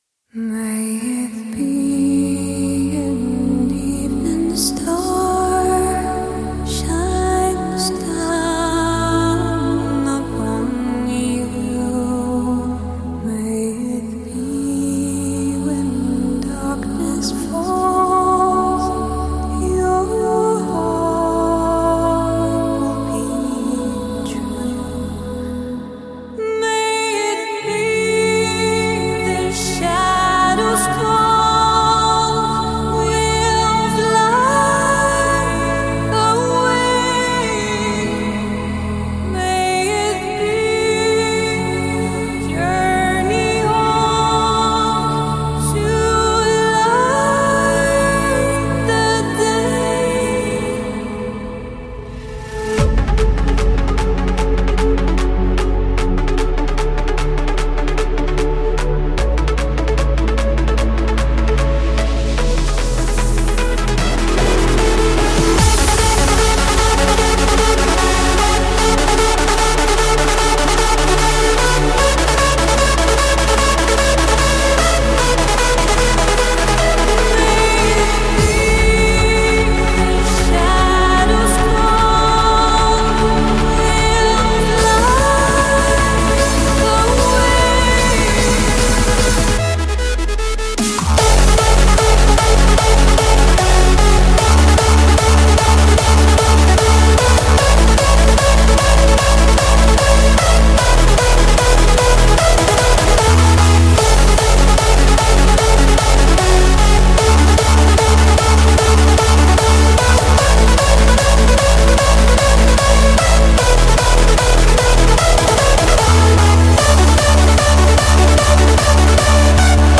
空灵的曲调，如天籁之音，如夜空之中闪烁的星。曲调平淡中略带伤感，又不失庄重。